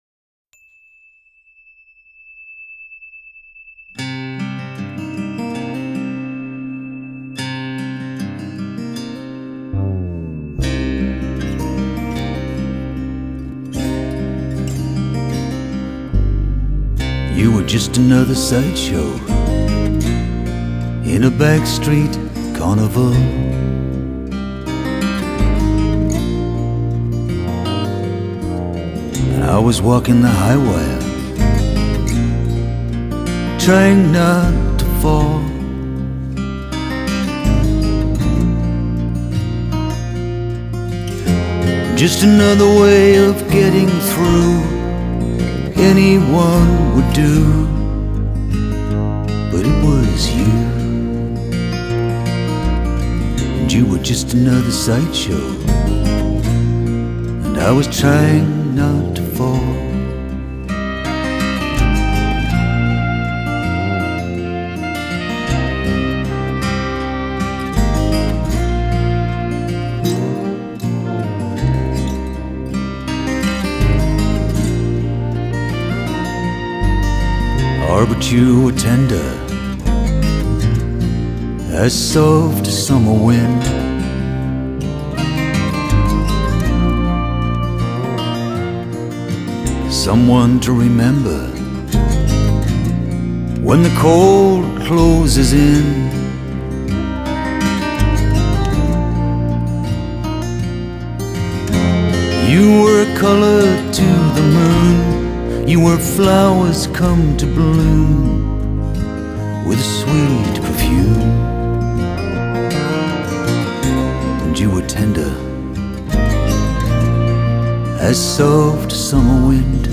加以B & W NAUTILUS 801 的MONITOR錄音效果，人聲和樂器的音像定位清晰而立體，三維護空間感覺強烈。
这张专辑的录音相当透明自然，嗓音轻慢而带着男声特有的沙粒感，吉他音色清脆圆润